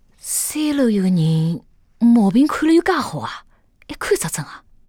c02_上海人A_2.wav